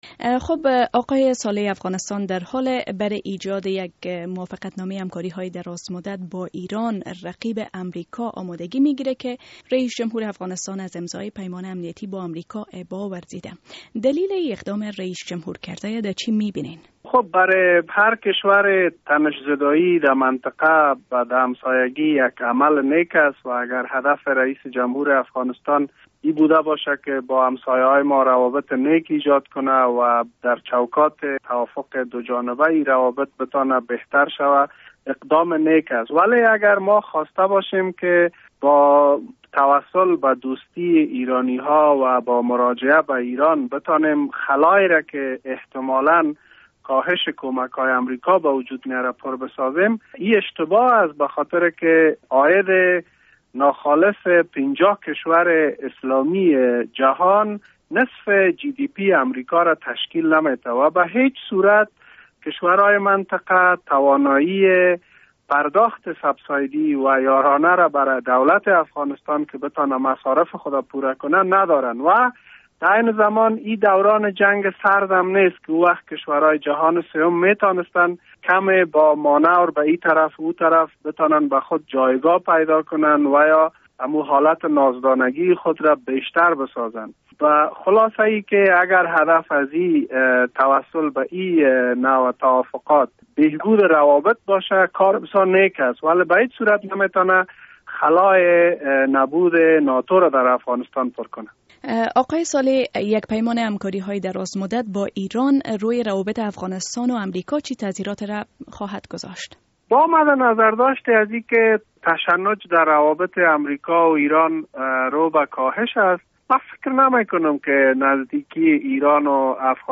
مصاحبه با امرالله صالح در مورد سفر اخیر حامد کرزی به ایران